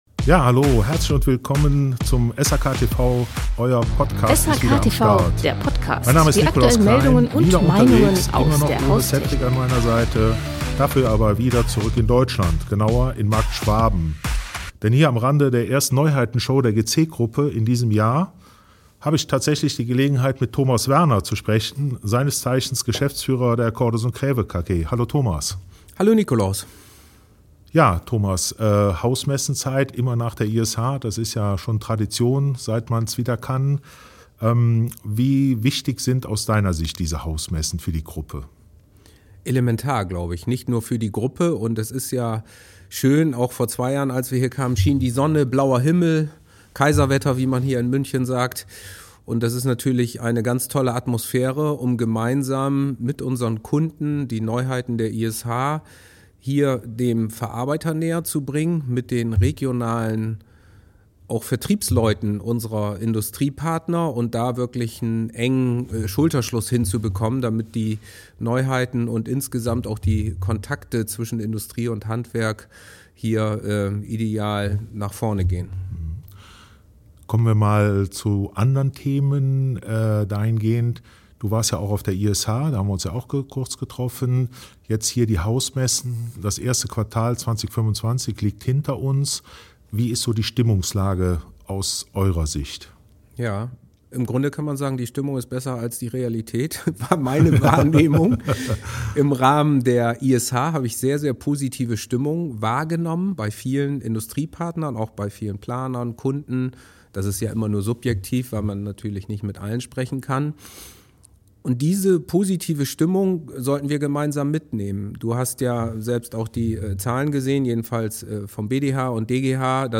Wir sind zu Gast bei der Neuheiten-Show der GC Gruppe in Marktschwaben